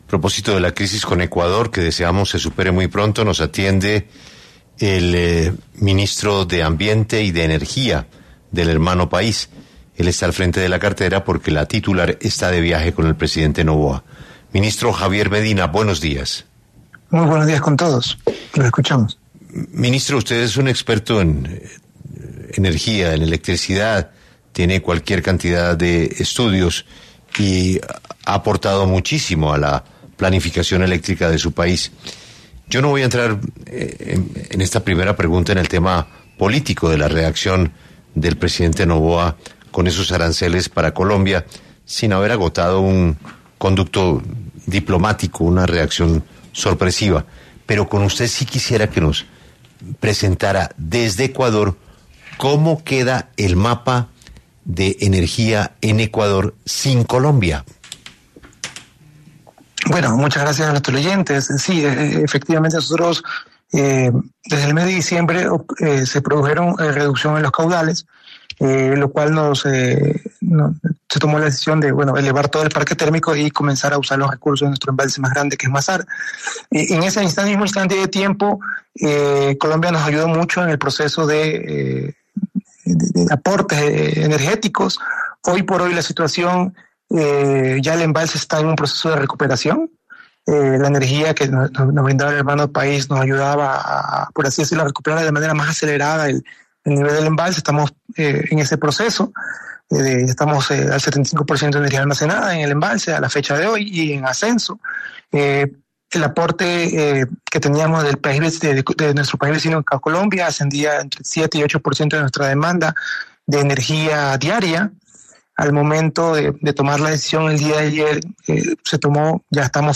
En entrevista con 6AM W, el ministro Javier Medina, encargado de la cartera de Ambiente y Energía de Ecuador, explicó que, aunque Colombia aportaba entre el 7 % y el 8 % de la demanda diaria de energía de Ecuador, el país ha logrado estabilizar su sistema eléctrico gracias a la recuperación de sus embalses y al uso del parque térmico.